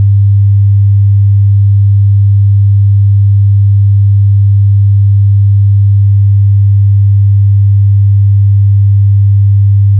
100 Hz Tone - This tone can serve two purposes.
If you switch the phase of one speaker system while playing this tone, the loudness of the tone will change.
100hzmono.wav